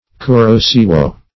Kuro-Siwo \Ku"ro-Siwo\, n. [Jap. kuroshio; kuro black + shio